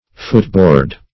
Footboard \Foot"board`\, n.